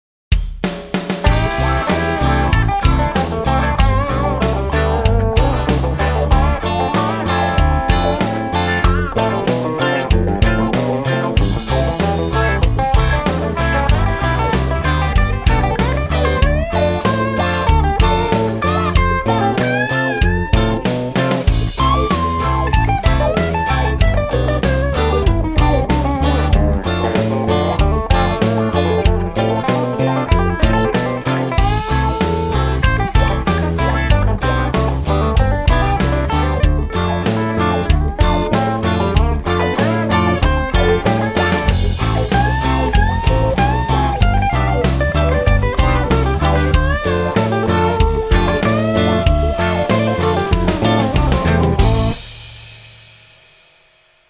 " View attachment Shred Reggae.mp3 " Its amazing how hard it is to play like this clean - very fast runs and tapping just don't ring out at all!